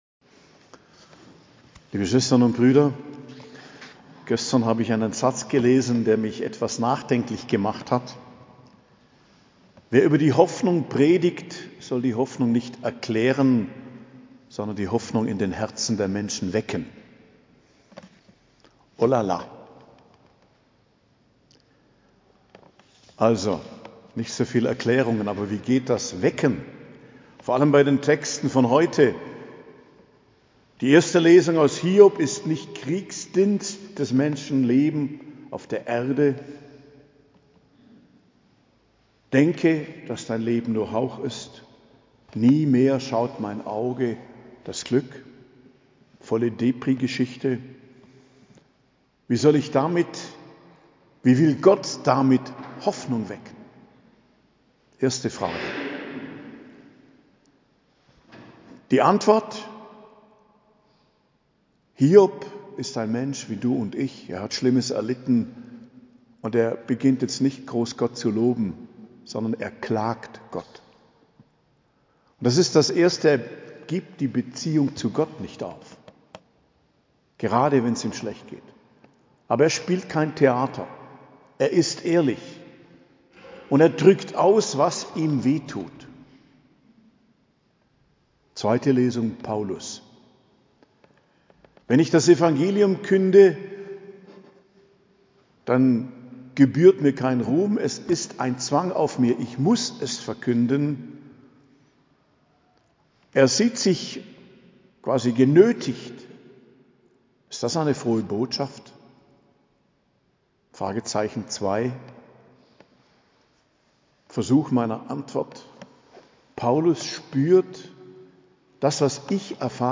Predigt zum 5. Sonntag i.J., 4.02.2024